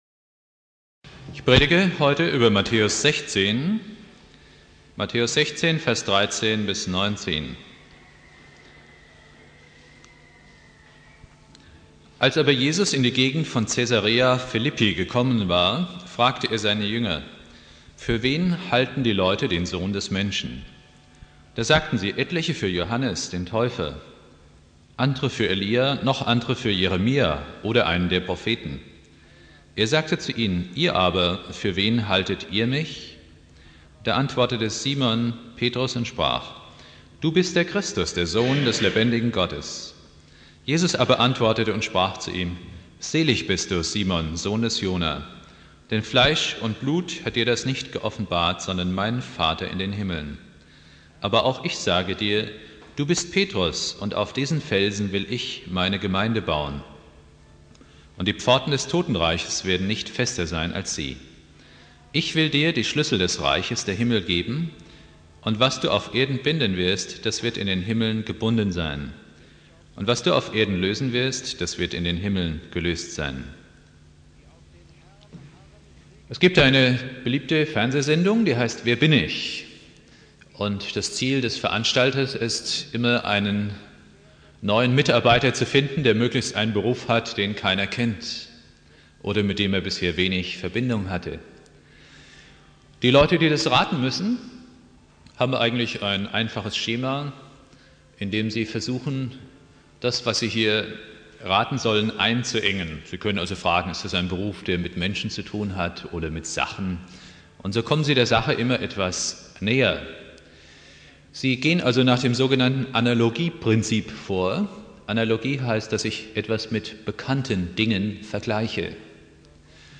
Predigt
Pfingstmontag